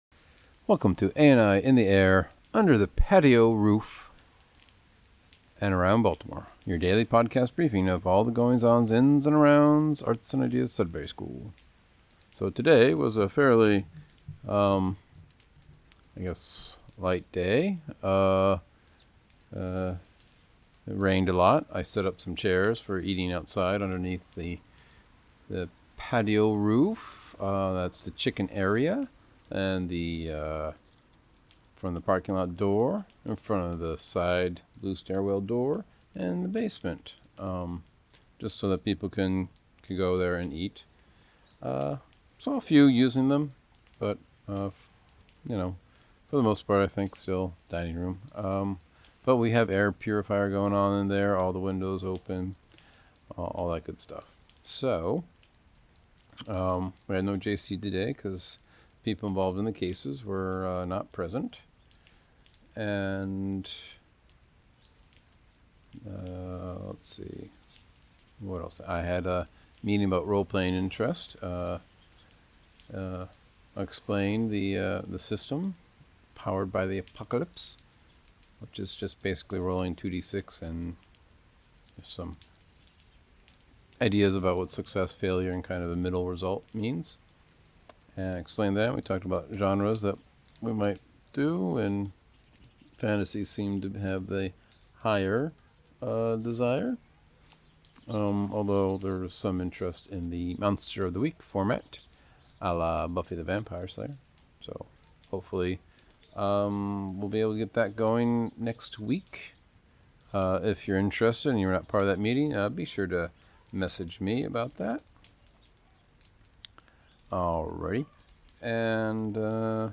Rainy day, podcast recorded under patio roof.